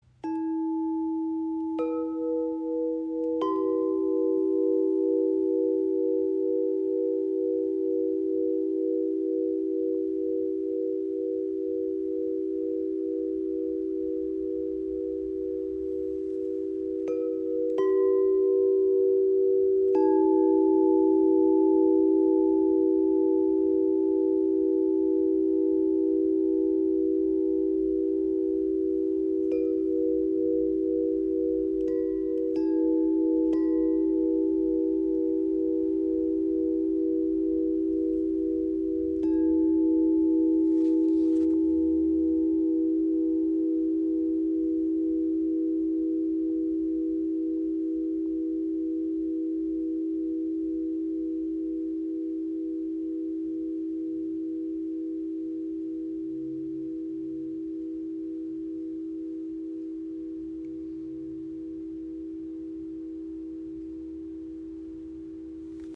Chordium L 35 in 432 Hz | Klangröhren in verschiedenen Dreiklang-Varianten
• Icon Drei harmonische Töne für weiche und zugleich kraftvolle Klangteppiche
Chordium L35 Klangröhren E minor | E4-G4-B in 432 Hz
E Moll (E G B): mitfühlend und zart, unterstützt beim Lösen von Schmerz oder angestauten Gefühlen.
Der Rohrdurchmesser von 35 mm schenkt dem Ton immer noch eine spürbare Tiefe und Länge.
Die Kombination aus Aluminiumröhren, die in 432 Hz gestimmt sind, und einem Rahmen aus Mahagoni oder Ebenholz verbindet Klarheit, Wärme und Erdung.